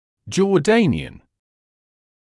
[ʤɔː’deɪnɪən][джоː’дэйниэн]иорданский